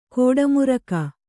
♪ kōḍamuraka